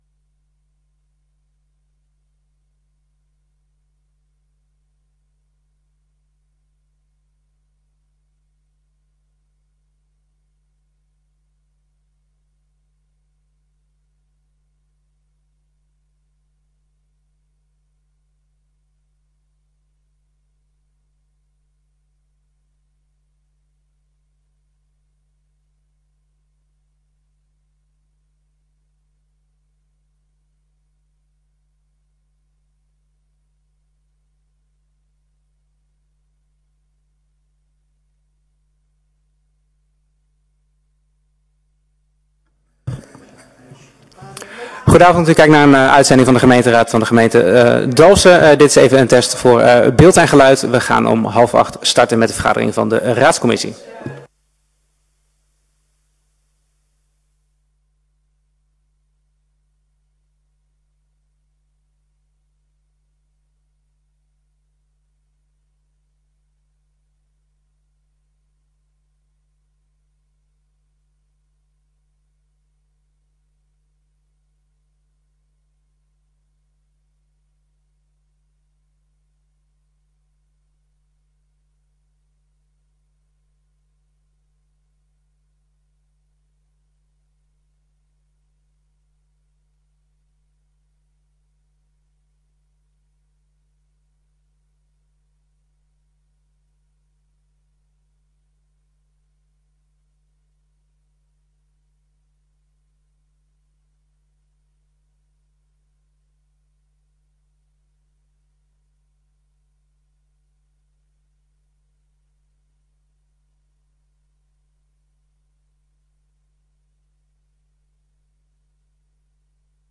Raadscommissie 22 januari 2024 19:30:00, Gemeente Dalfsen
Download de volledige audio van deze vergadering